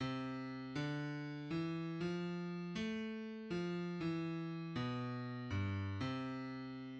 {\clef bass \tempo 4=120 c4. d8 ~ d4 e f4. a8 ~ a4 f e4. b,8 ~ b,4 g, c2}\midi{}